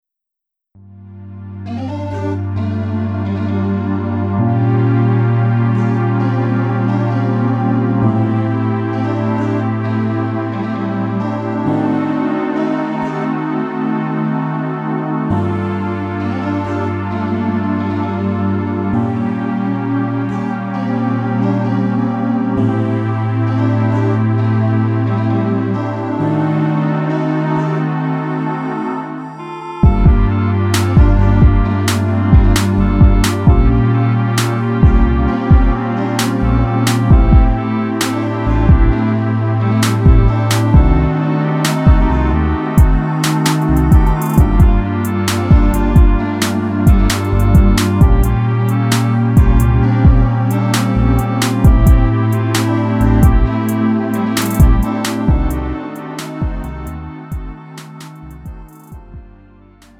음정 원키 3:56
장르 구분 Lite MR